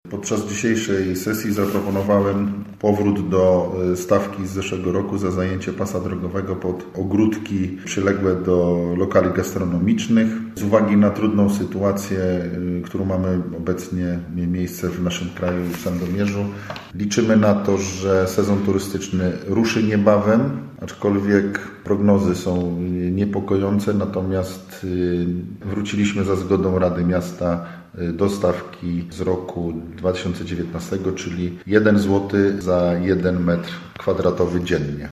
Mówi burmistrz Marcin Marzec: